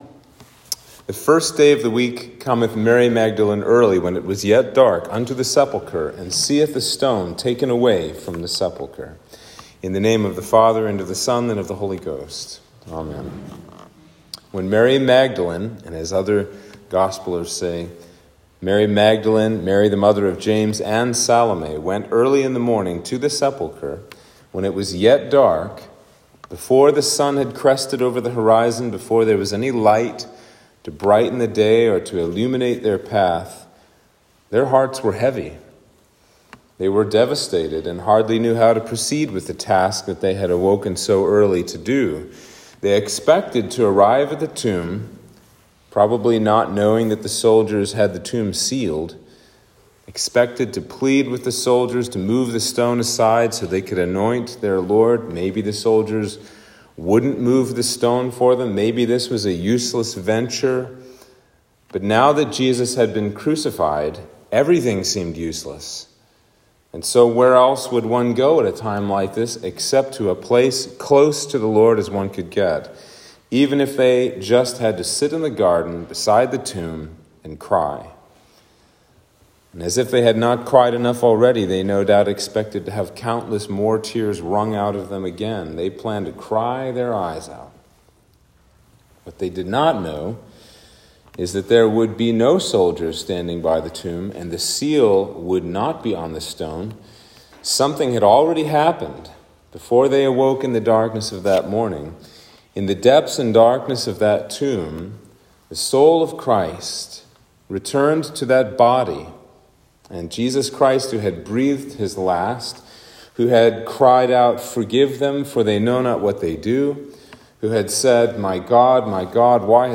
Sermon for Easter Day